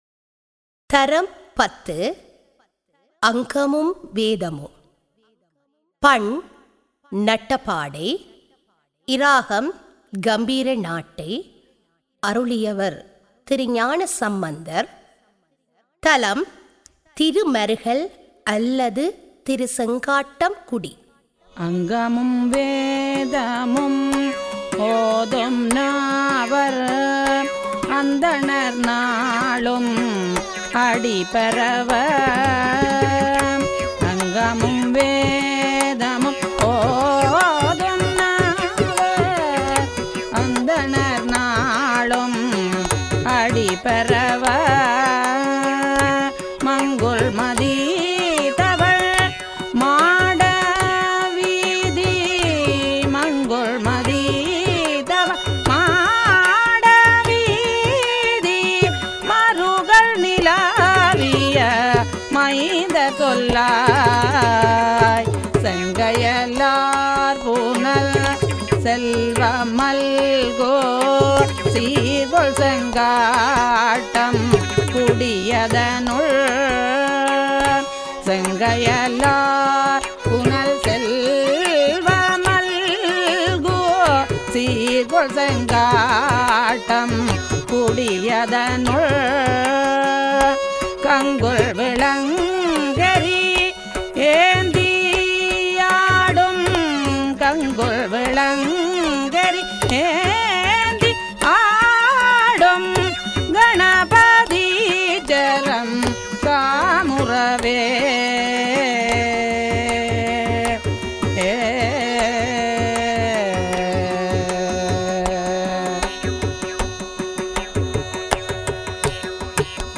தரம் 10 - சைவநெறி - அனைத்து தேவாரங்களின் தொகுப்பு - இசைவடிவில்